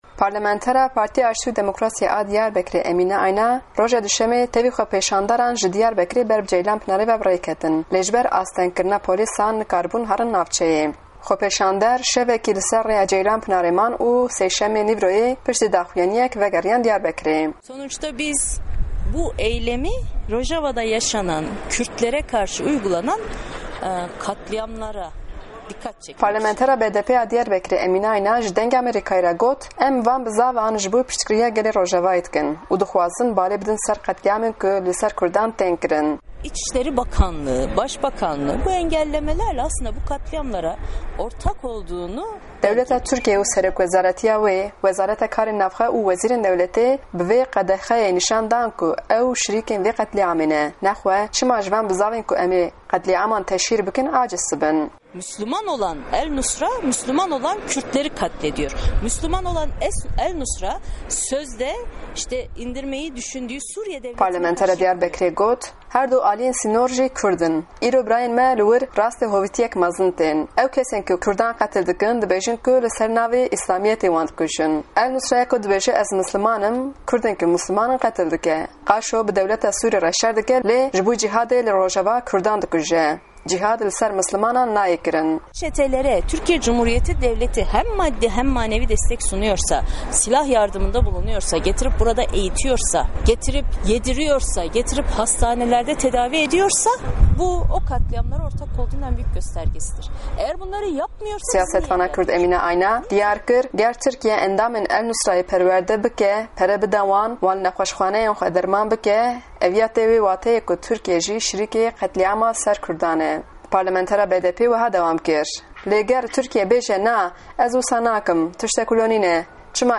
Hevpeyvîn bi parlamenter Emîne Ayna re